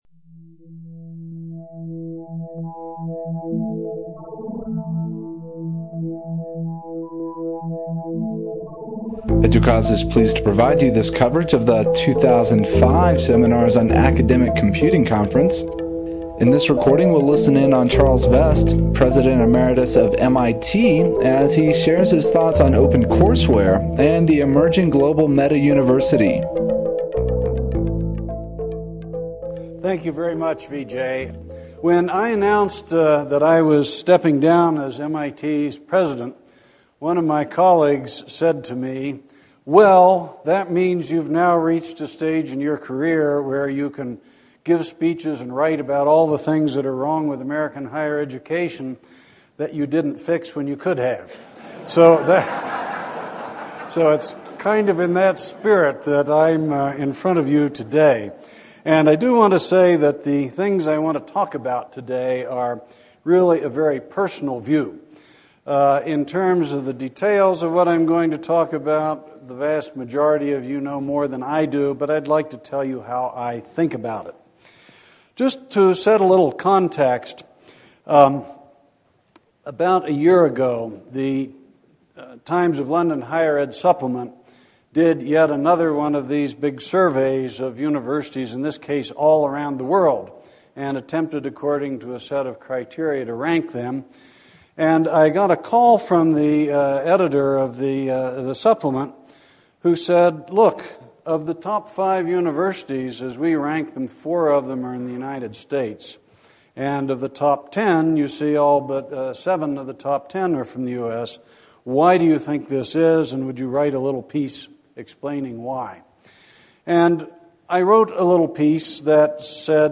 Open Courseware and the Emerging Global Meta University Charles M. Vest President Emeritus, MIT Seminars on Academic Computing (SAC) 2005 , Snowmass, Colorado, August ??, 2005 Claire Maple Address